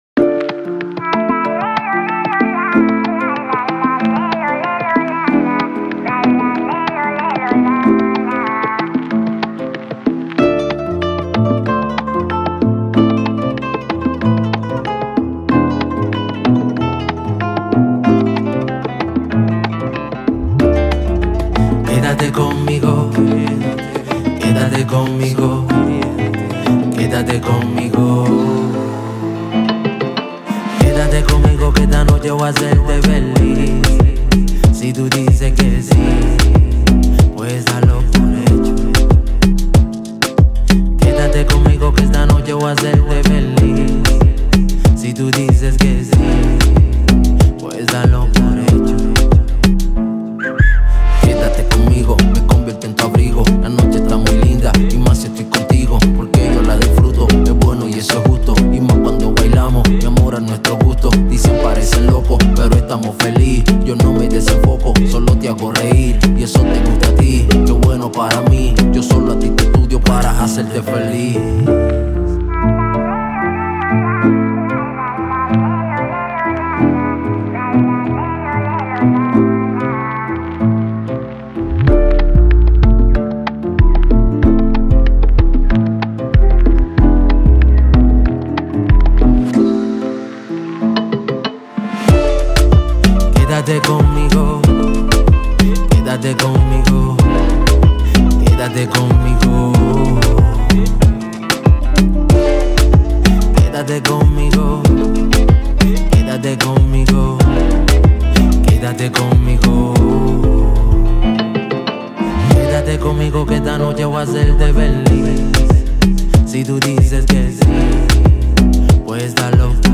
это зажигательная песня в жанре латинской поп-музыки